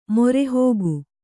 ♪ morehōgu